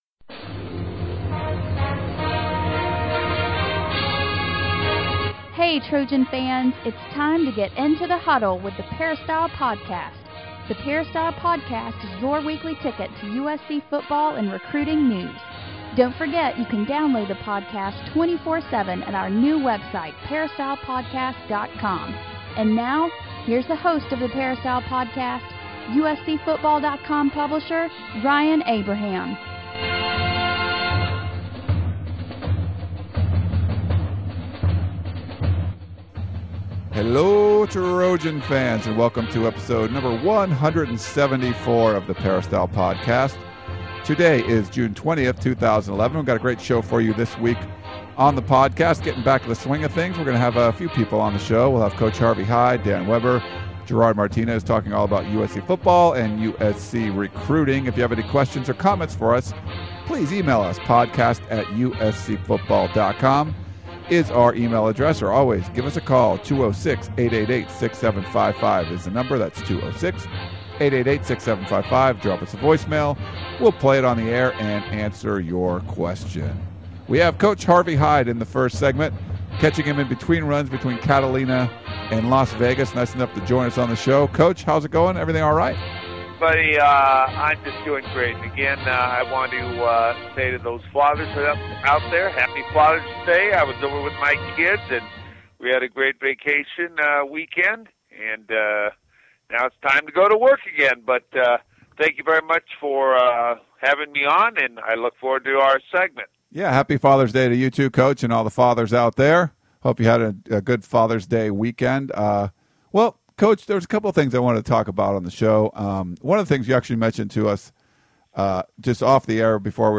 We have three guests on the podcast this week talking about Trojan offseason workouts, the success of the USC athletic department and recruiting, including some of the best prospects at the BMOC passing tournament at the Home Depot Center.